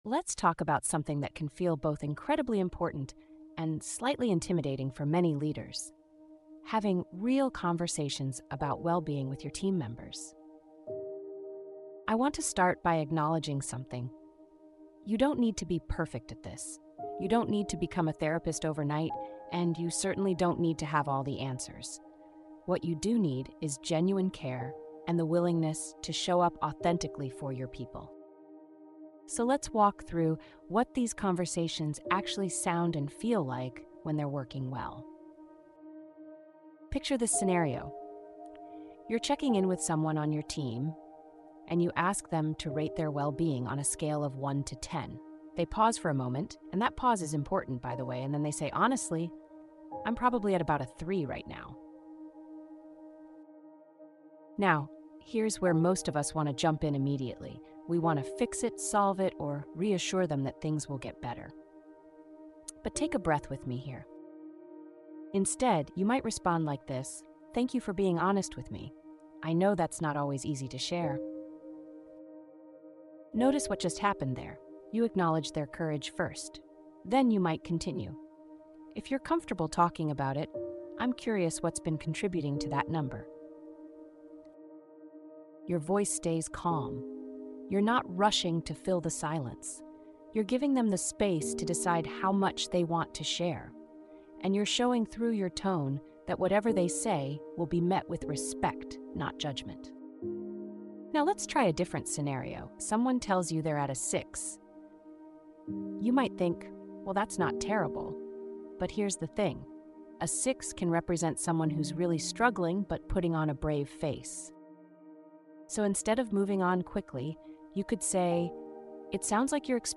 Hear how tone, pacing, and warmth can transform a wellbeing conversation: showing care without rushing to solutions.